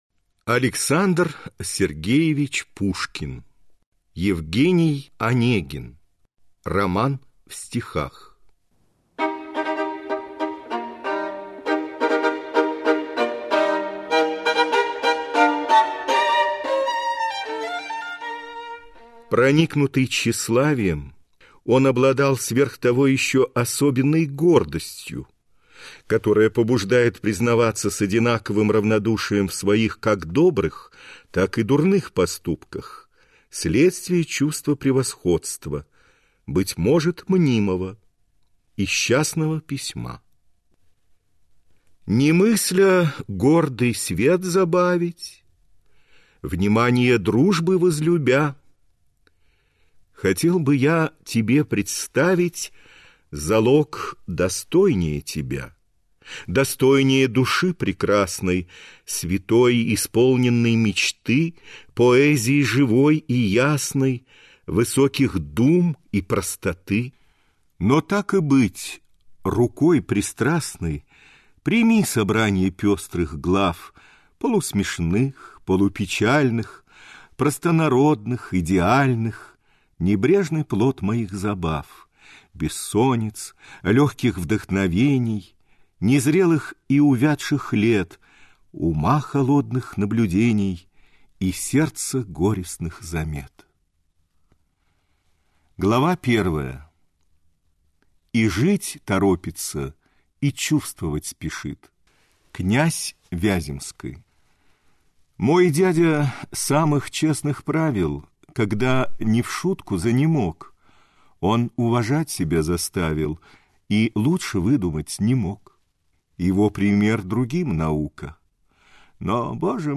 Аудиокнига Евгений Онегин - купить, скачать и слушать онлайн | КнигоПоиск